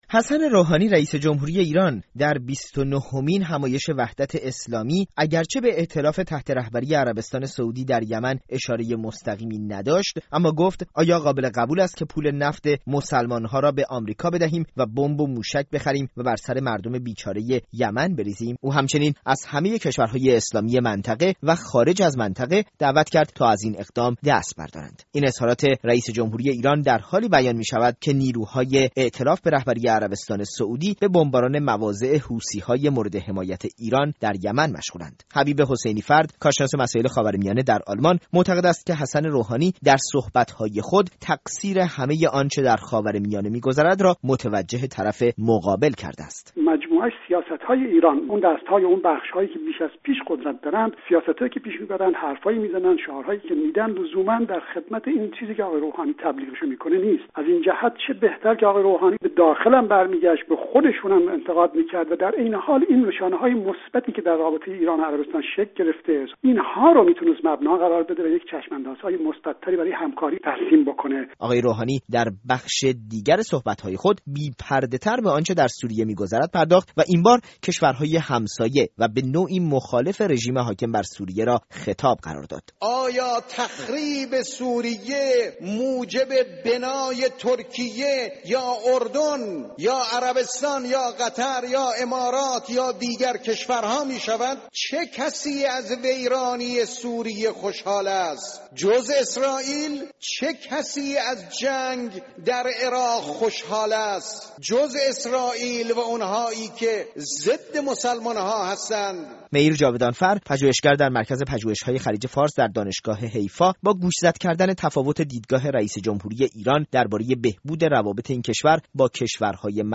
از رادیو فردا